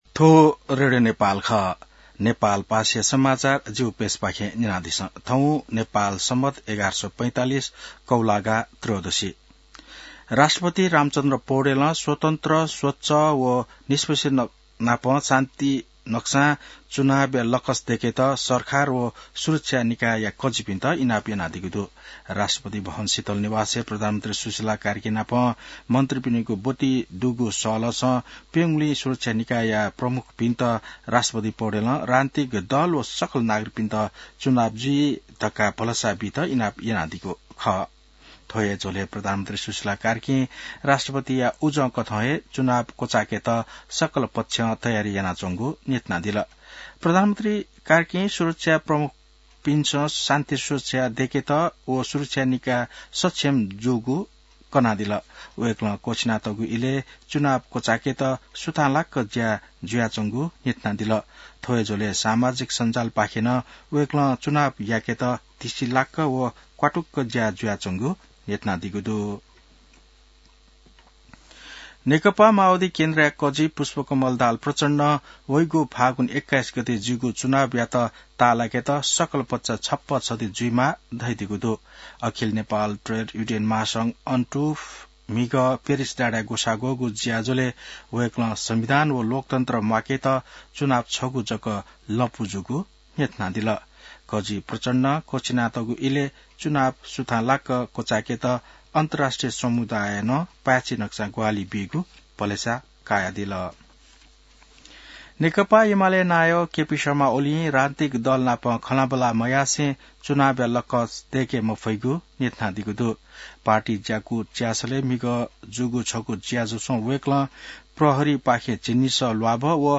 नेपाल भाषामा समाचार : १८ पुष , २०२६